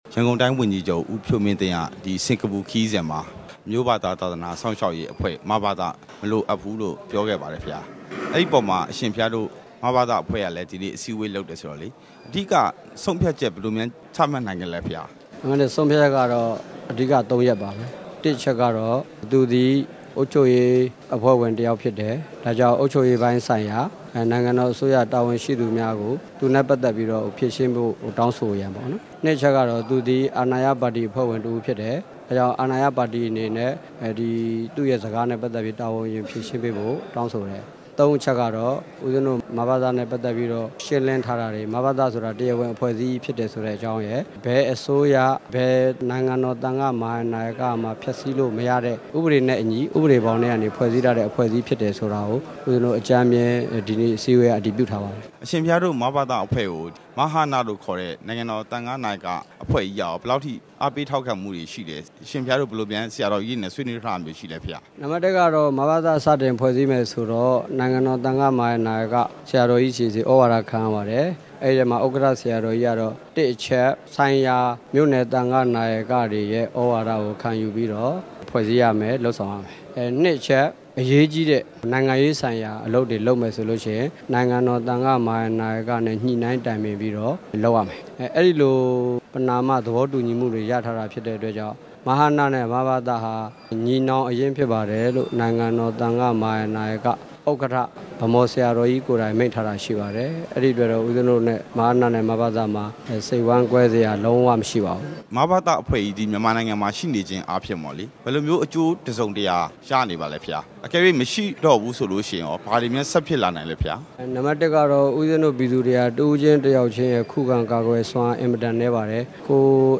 မဘသ မလိုအပ်ကြောင်း ဦးဖြိုးမင်းသိန်း ပြောကြားမှုအပေါ် ဦးဝီရသူနဲ့ မေးမြန်းချက်